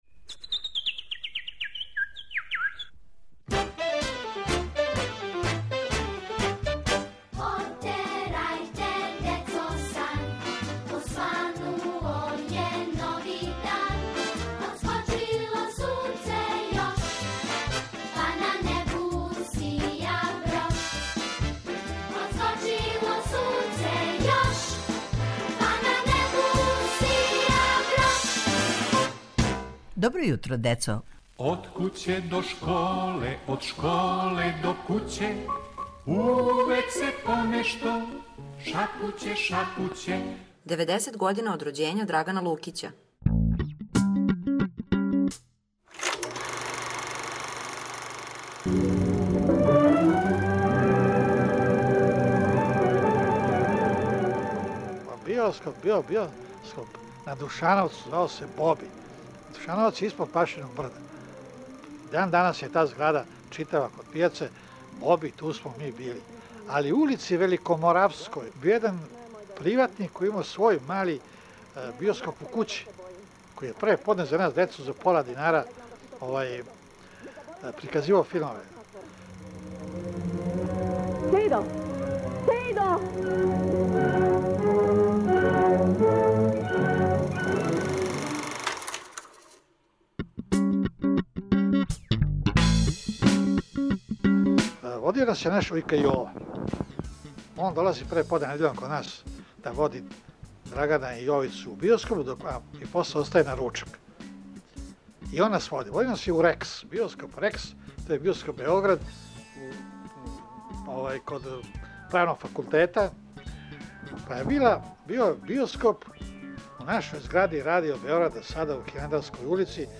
За ову прилику, после 30 година, имамо специјално компоновану музику одабрану на нашем конкурсу за младе композиторе.